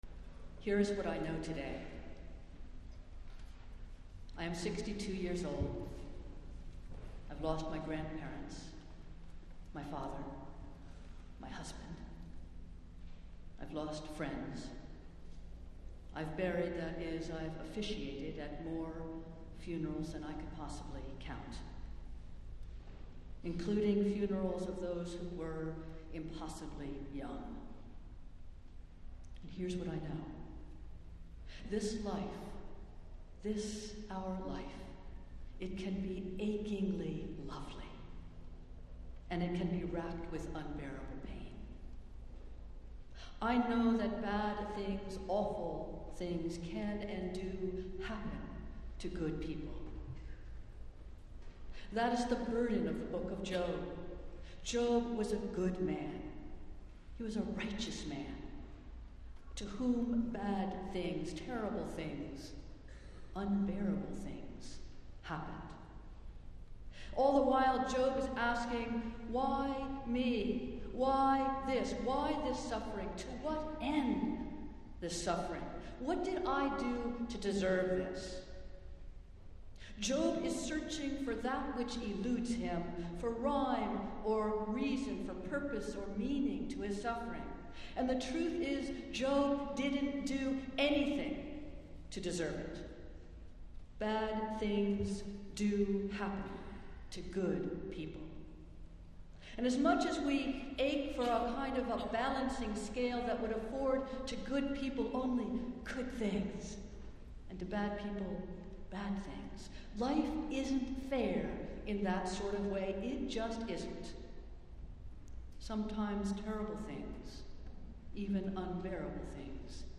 Festival Worship - Marathon Sunday